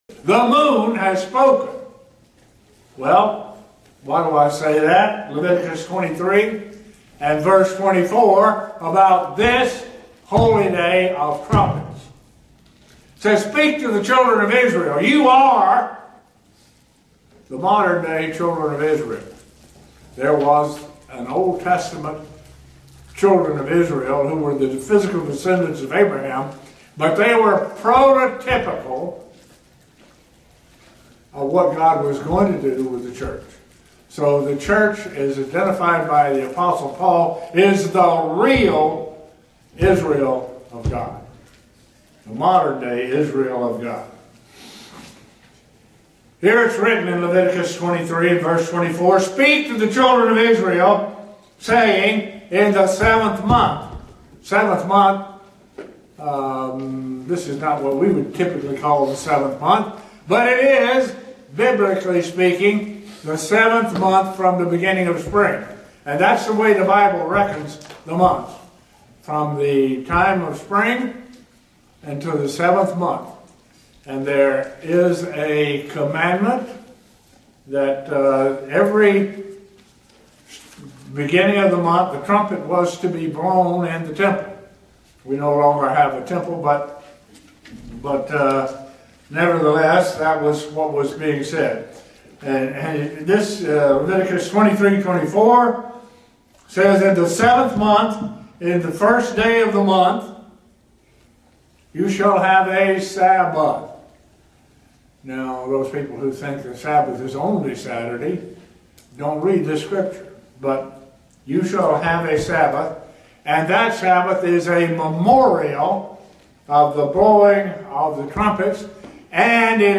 Sermons
Given in Buffalo, NY Elmira, NY